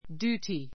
duty djúːti デュ ーティ 名詞 複 duties djúːtiz デュ ーティ ズ ❶ （法律上・道義上の） 義務 do one's duty do one's duty 自分の義務を果たす, 本分を尽 つ くす It's our duty to obey the laws.